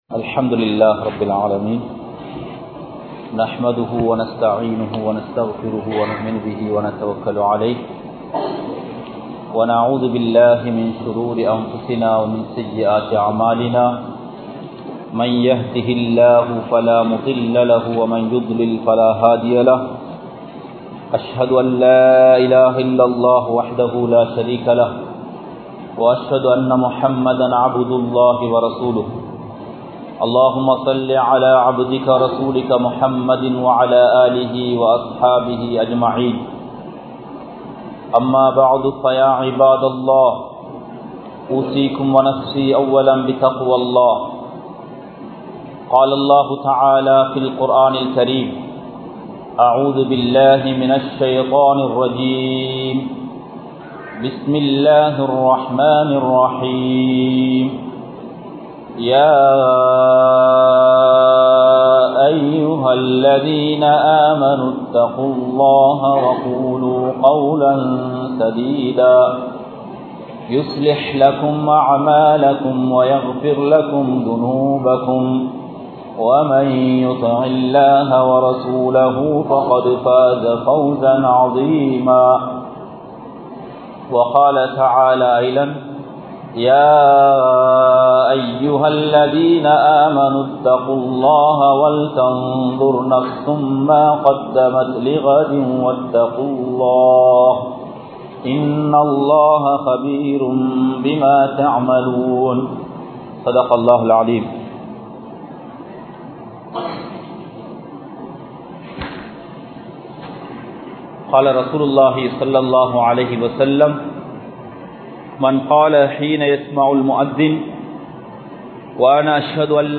Tholuhaikku Mun Pin Ulla Amalhal (தொழுகைக்கு முன்பின்னுள்ள அமல்கள்) | Audio Bayans | All Ceylon Muslim Youth Community | Addalaichenai
Colombo 15, Mattakkuliya, Zawiya Jumua Masjidh